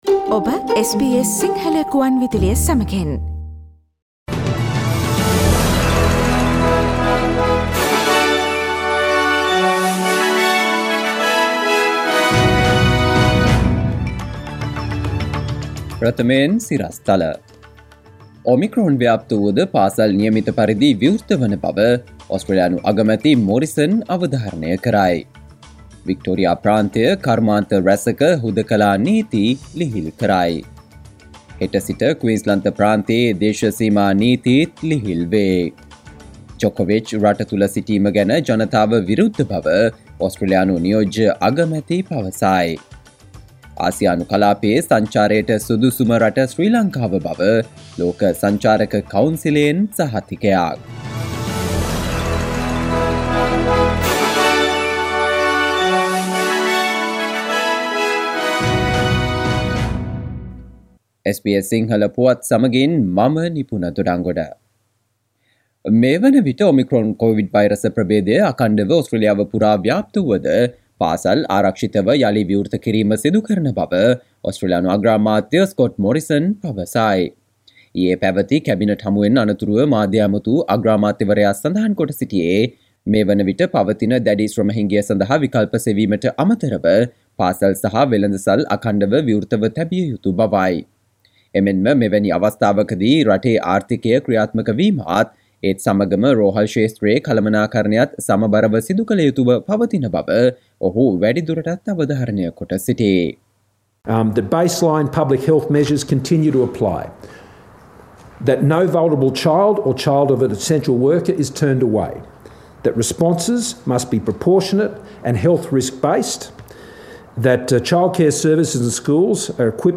සවන්දෙන්න 2022 ජනවාරි 14 වන සිකුරාදා SBS සිංහල ගුවන්විදුලියේ ප්‍රවෘත්ති ප්‍රකාශයට...